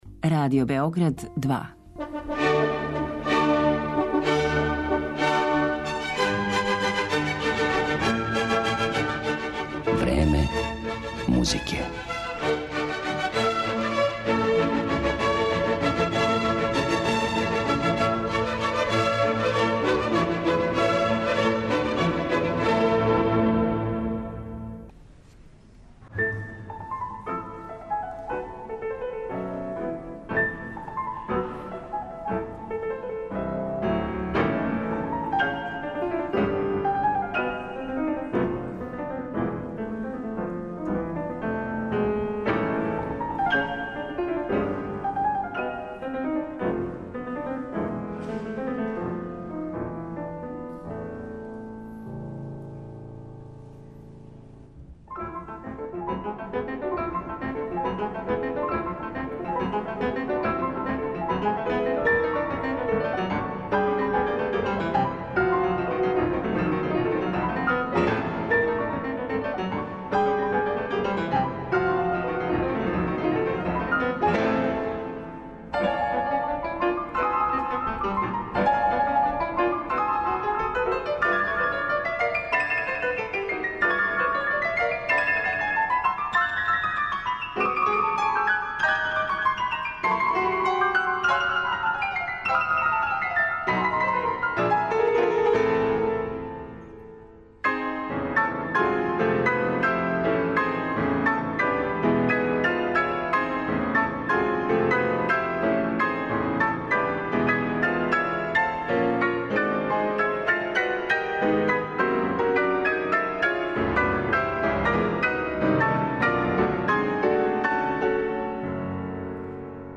пијанистичком дуу